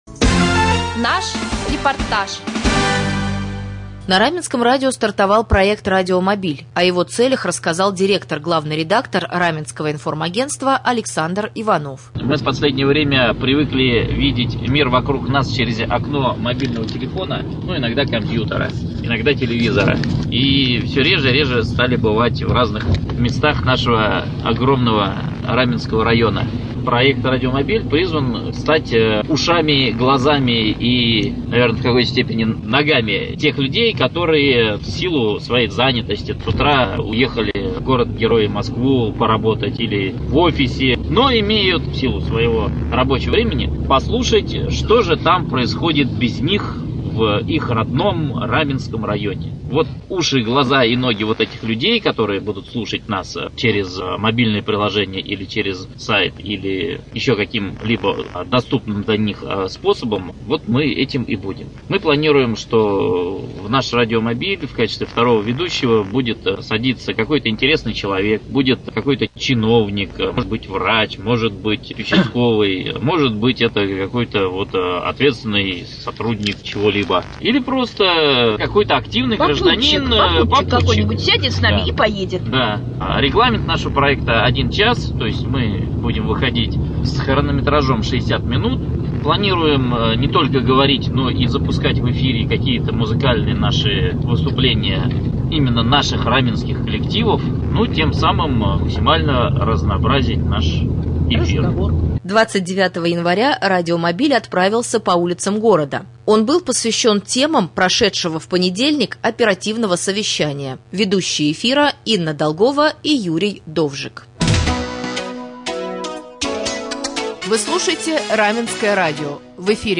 4.Рубрика «Специальный репортаж». 29 января на Раменском радио стартовал проект радиомобиль.